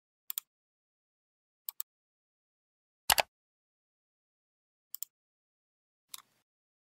Mouse Click Sound Effects
High quality click sound effects for you to use in any project.
mouse-click-sound-effects.mp3